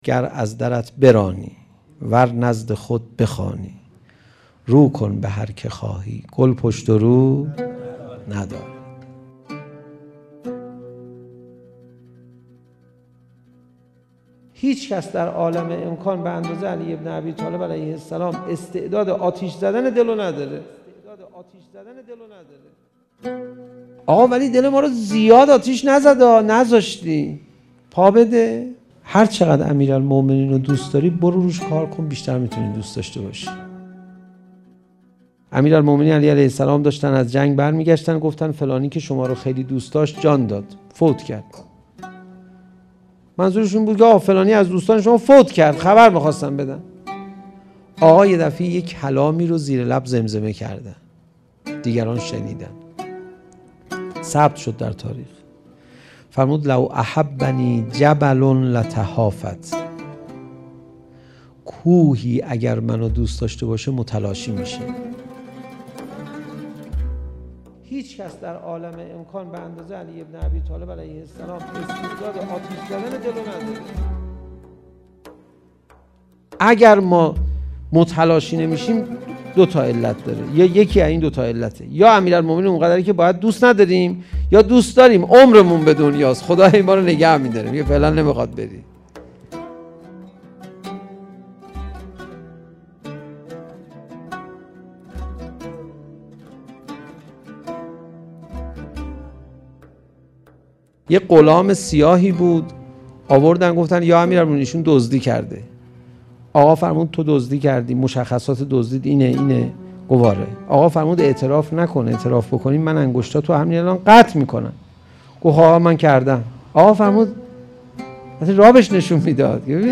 منبع: اتحادیه پیچ و مهره فروشها - سال92